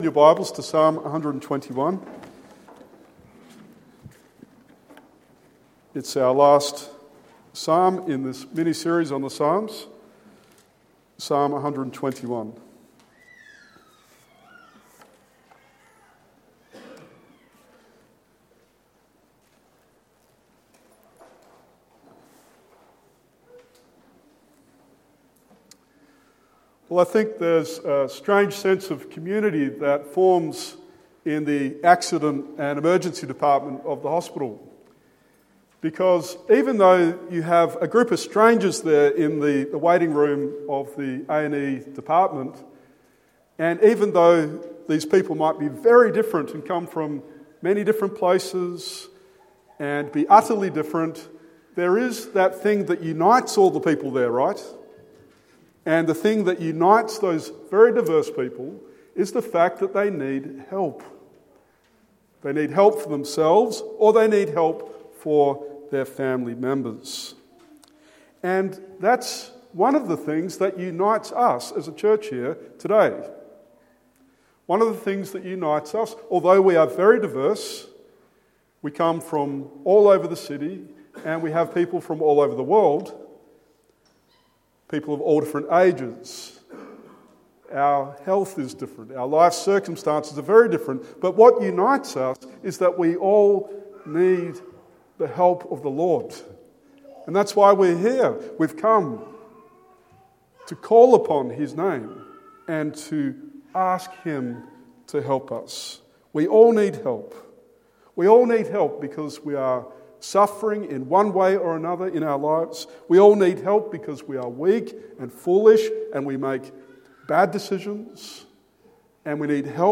This sermon was prepared with love, with our oldest church member especially in mind. Her thoughful questions about Psalm 121 inspired me to dig into it very deeply.